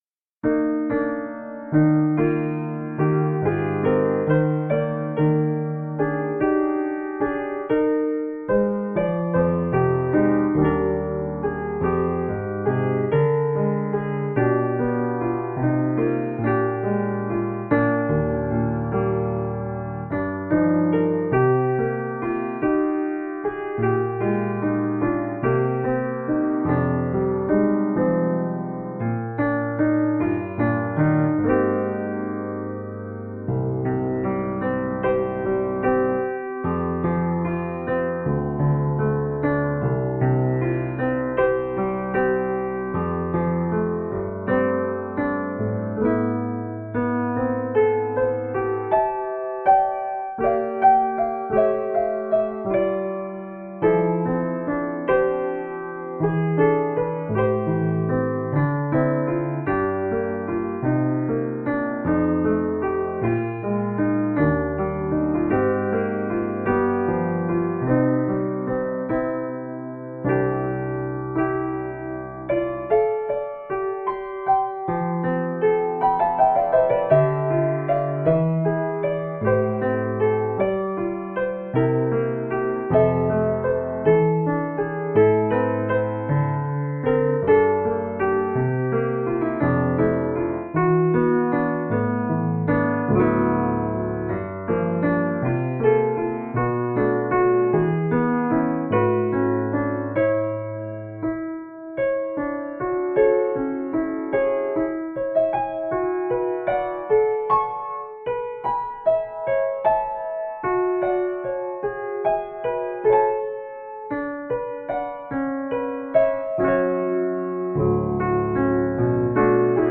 Magical, heart inspiring music.